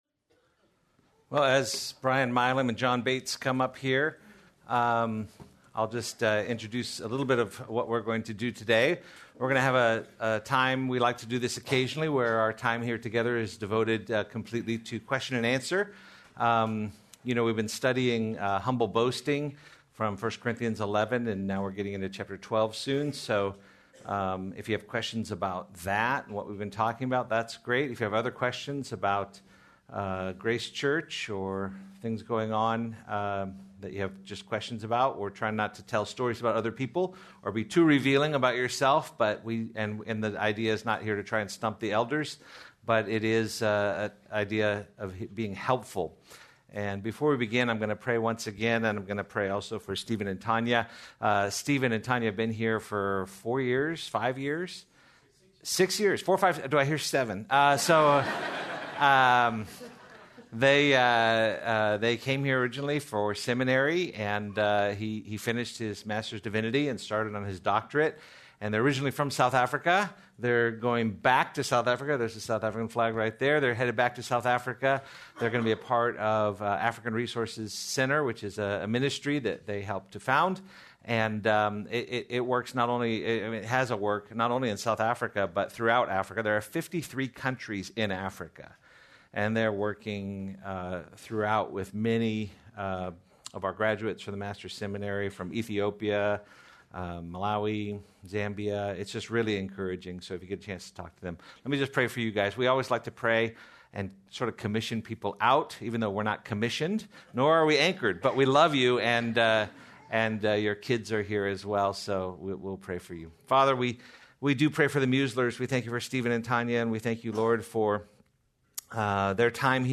Elder Q&A
Please note, due to technical difficulties, this recording skips brief portions of audio.